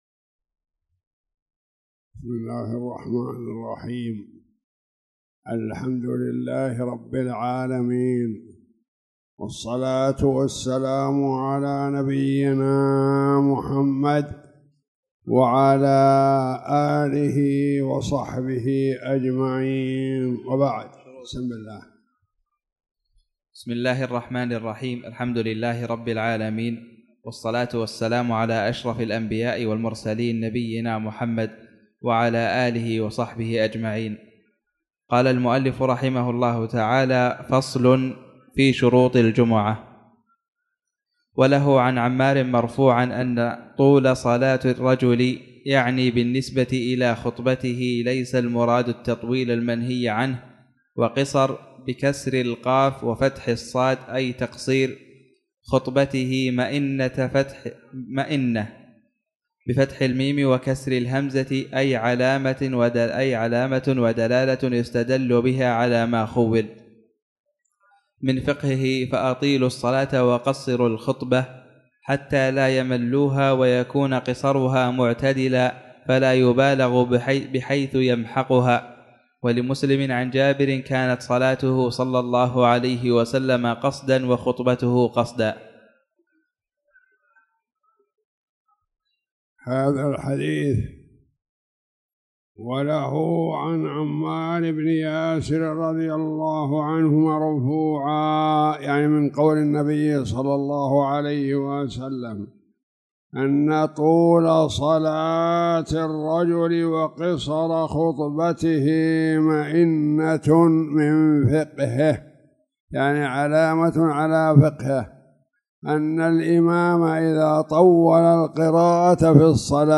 تاريخ النشر ٣٠ ربيع الثاني ١٤٣٨ هـ المكان: المسجد الحرام الشيخ